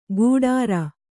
♪ gūḍāra